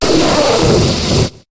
Cri de Maganon dans Pokémon HOME.